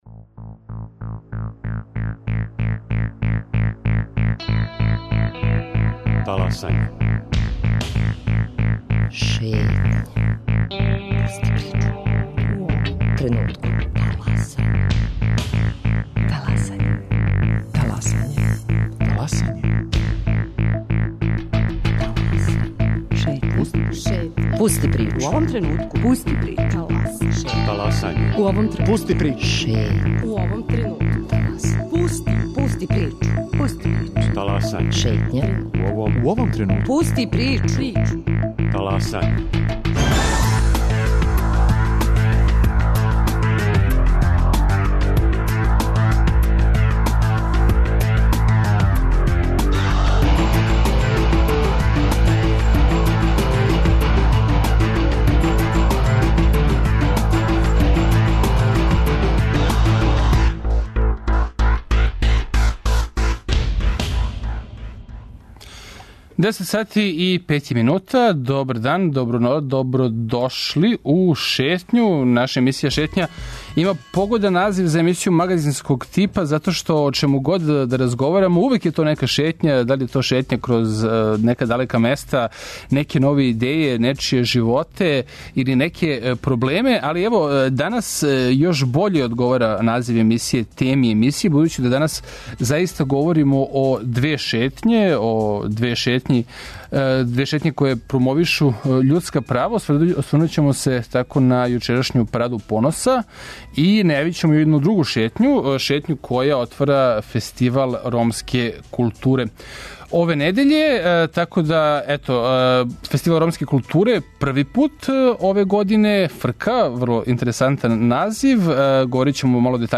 У наставку Шетње слушамо разговоре које смо забележили током јучерашње Параде поноса.